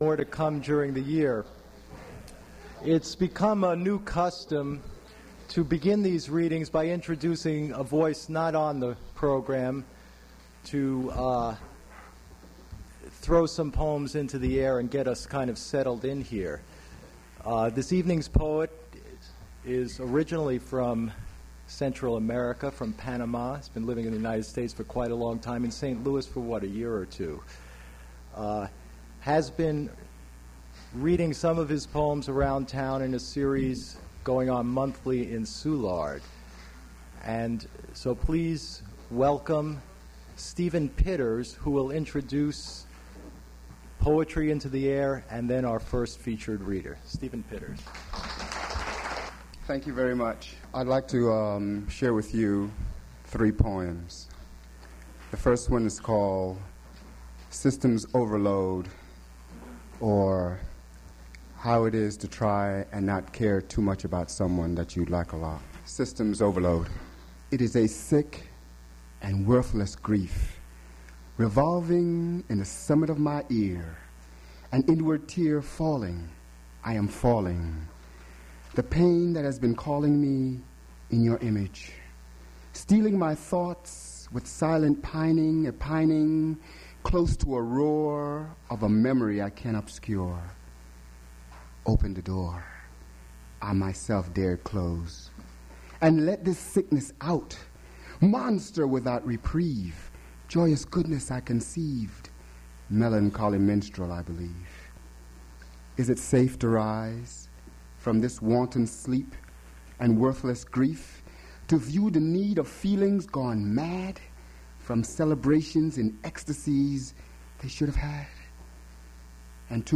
• mp3 edited access file was created from unedited access file which was sourced from preservation WAV file that was generated from original audio cassette.
• recording starts mid-sentence; Not sure what the title of the poet at 03:25;